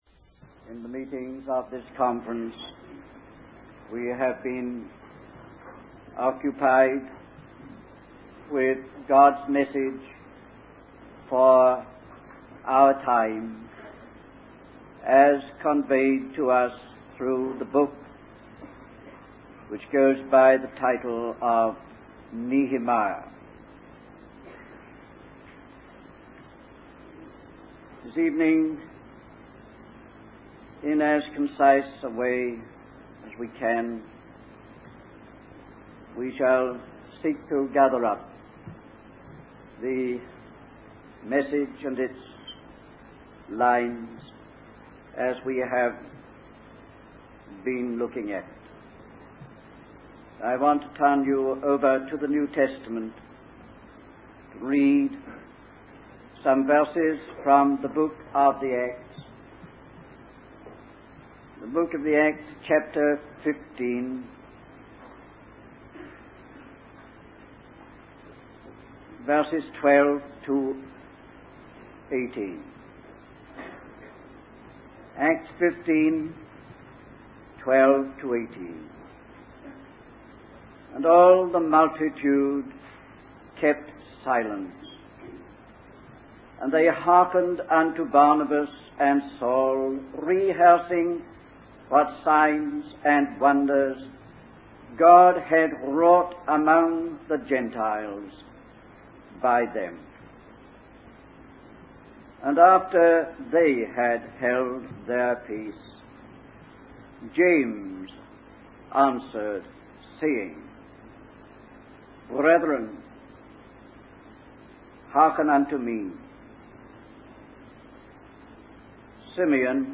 In this sermon, the speaker focuses on the book of Nehemiah and its relevance to our lives today.